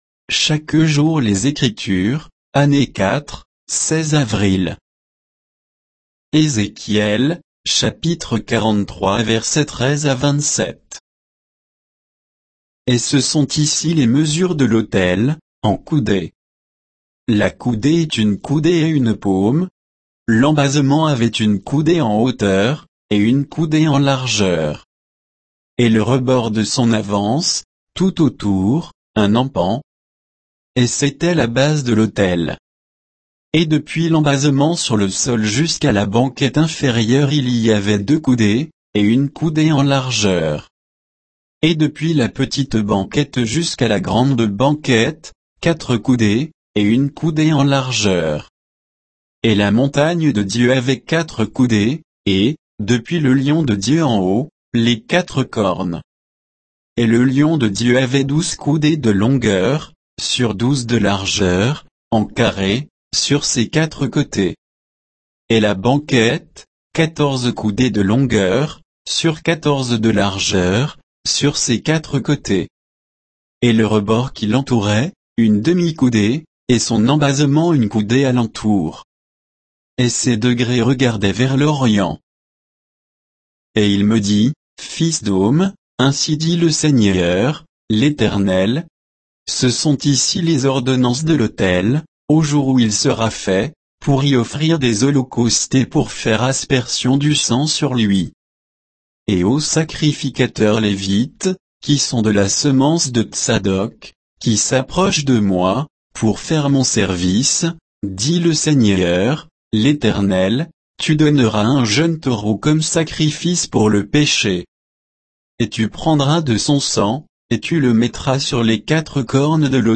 Méditation quoditienne de Chaque jour les Écritures sur Ézéchiel 43, 13 à 27